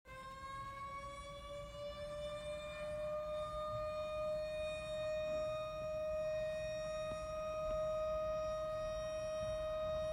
Outdoor Warning Sirens
A loud piercing high pitched whine sound that can be heard outdoors. Sirens typically sounds continuously for 2 to 3 minutes before pausing and restarting if the hazard has not yet passed.
grfd-station-7-siren.mp3